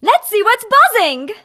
bea_start_vo_02.ogg